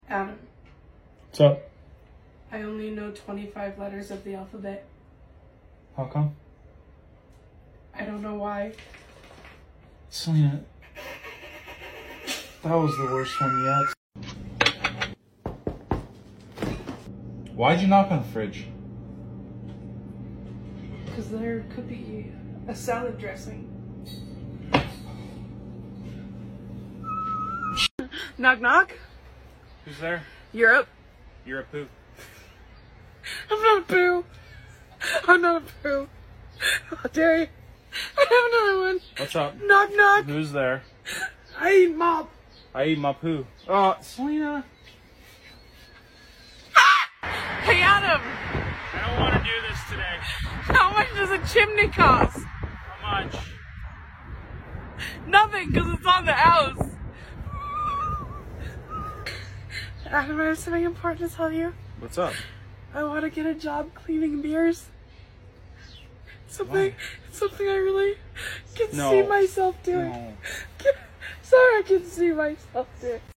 Her laugh is contagious